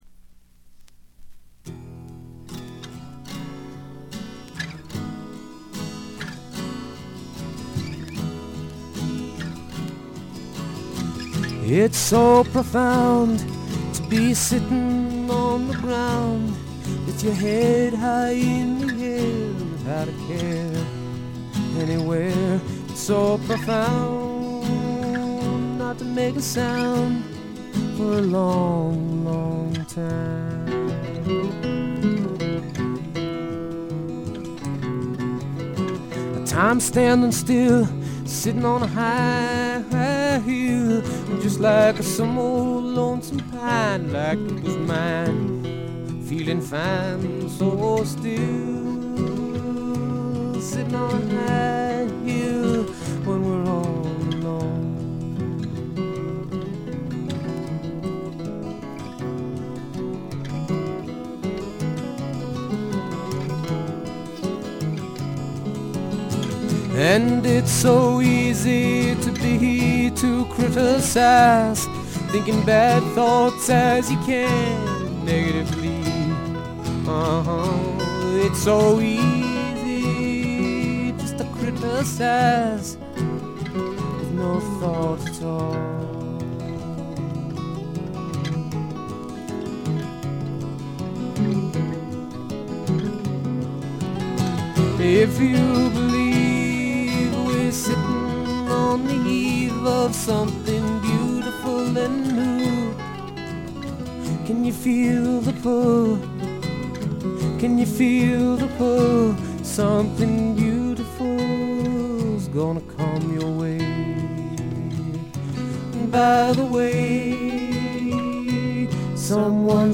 プレスのせいかチリプチ少々出ます。
本人のギター弾き語りを基本に友人たちによるごくシンプルなバックが付くだけのフォーキーな作品です。
とてもおだやかでドリーミーな感覚もがただようフォーク作品です。
試聴曲は現品からの取り込み音源です。
ちなみに試聴曲はA7以外は女性ヴォーカルとのデュエットです。
guitar